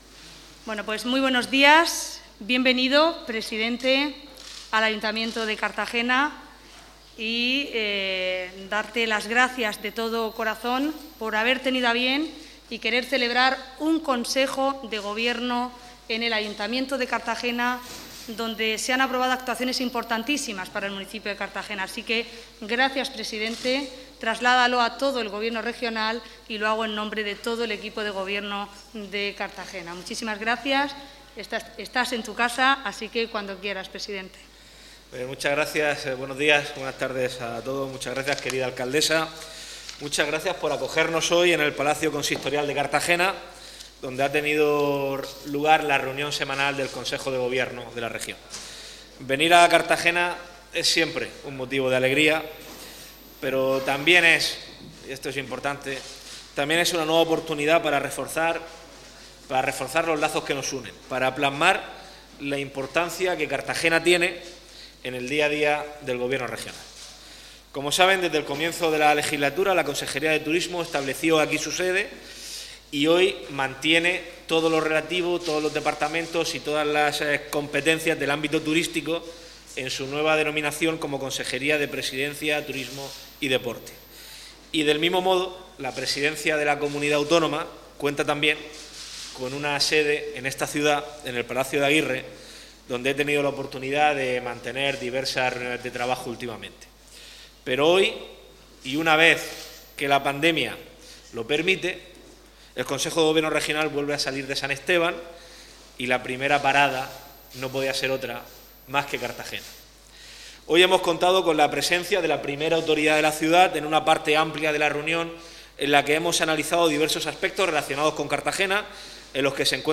Audio: Declaraciones de Ana Bel�n Castej�n y Fernando L�pez Miras (MP3 - 21,06 MB)